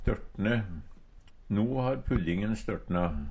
størtne - Numedalsmål (en-US)
DIALEKTORD PÅ NORMERT NORSK størtne stivne Infinitiv Presens Preteritum Perfektum størtne størtna størtna størtna Eksempel på bruk No har puddingen størtna.